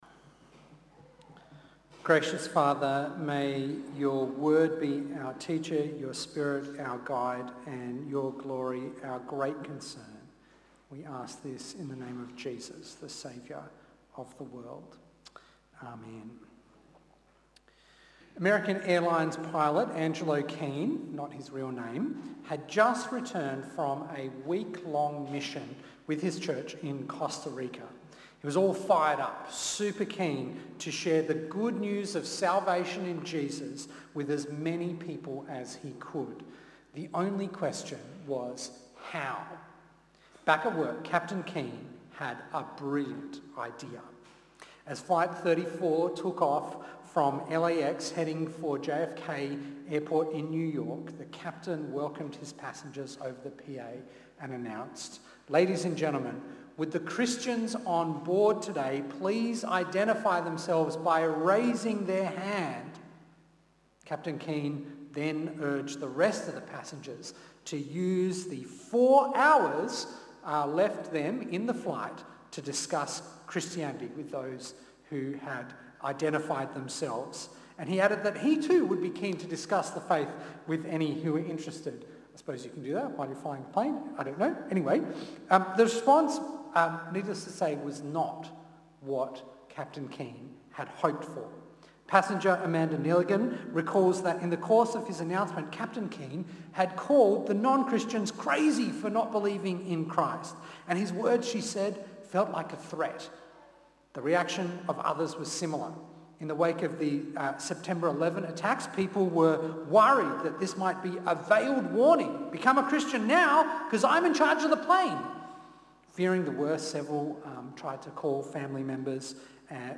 2025 Giving a reason for the hope you have Preacher